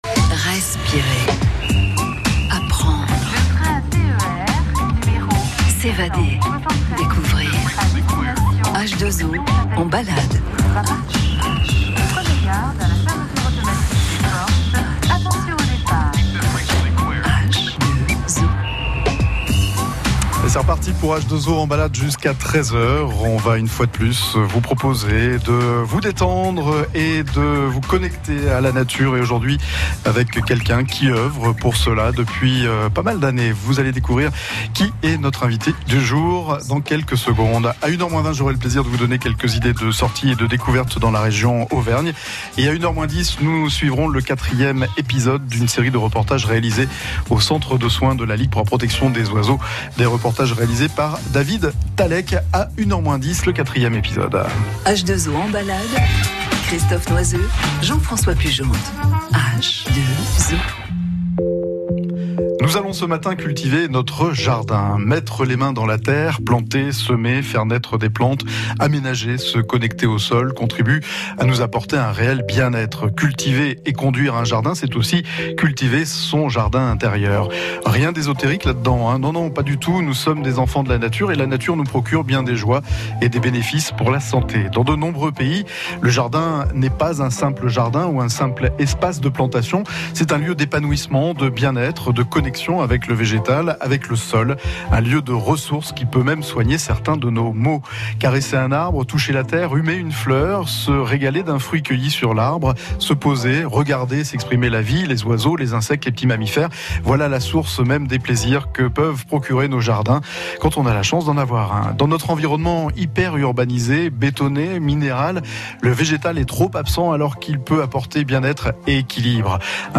Ecoutez mon entretien dans "L'invité H2O" de France Bleu Auvergne